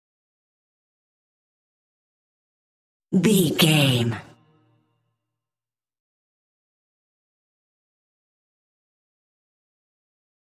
Ionian/Major
electronic
techno
synths
industrial